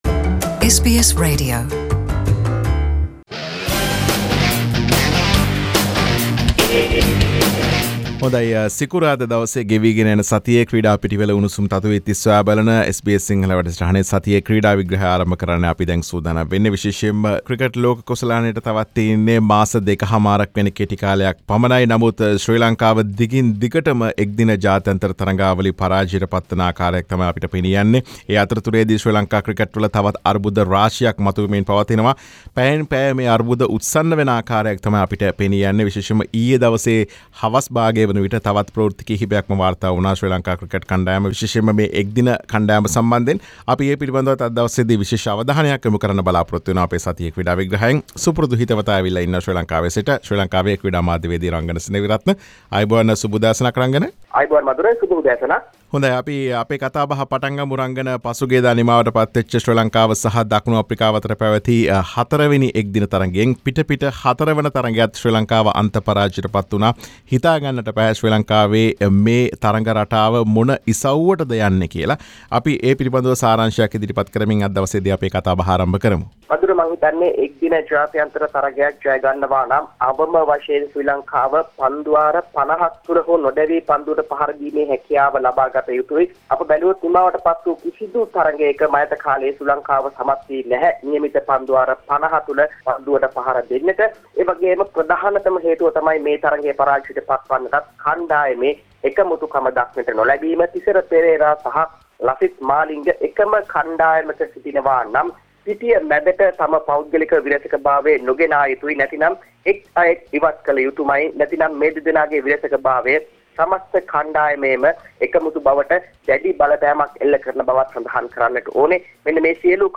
Sports journalist